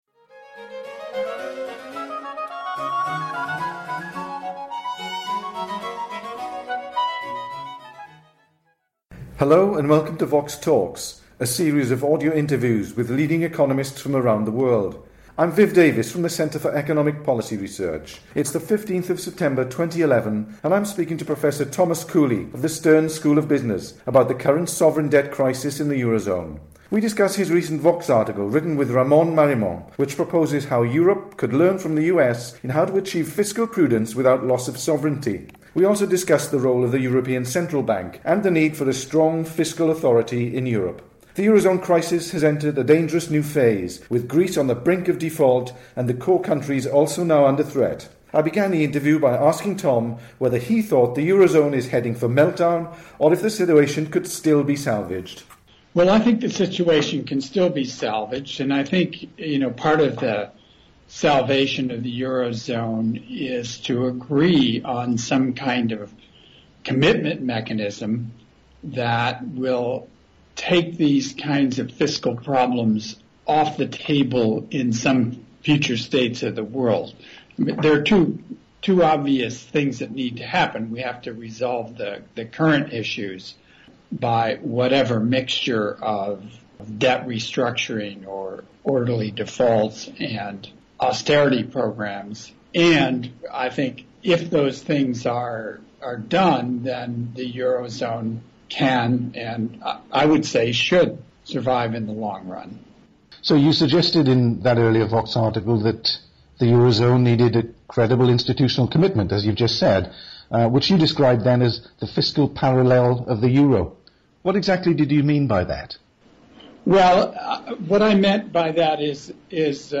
The interview was recorded on 15 September 2011.